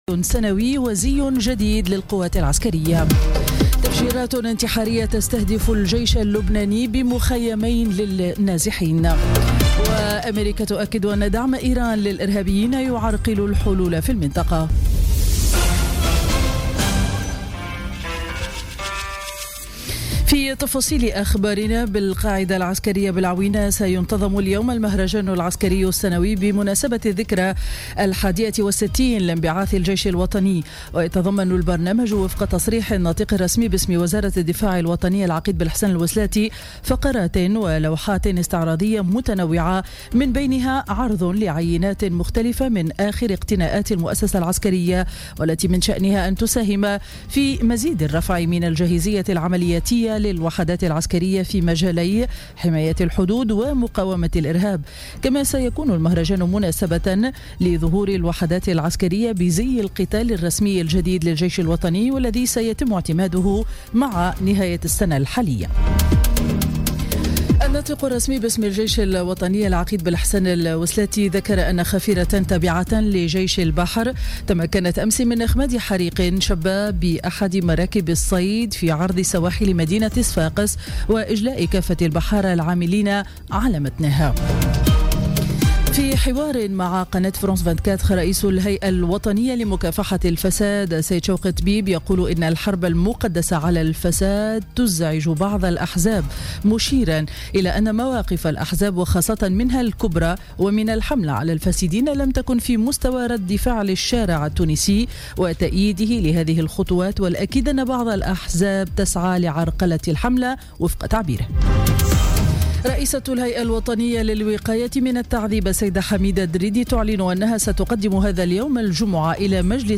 نشرة أخبار السابعة صباحا ليوم الجمعة 30 جوان 2017